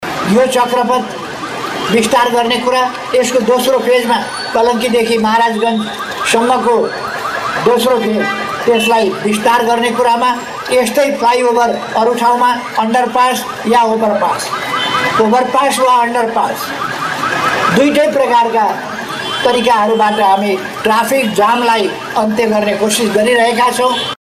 काठमाडौं — ललितपुरको ग्वार्कोमा निर्माण सम्पन्न भएको ओभरपासको शुक्रवार औपचारिक उद्घाटन प्रधानमन्त्री केपी शर्मा ओलीको प्रमुख आतिथ्यमा सम्पन्न भयो।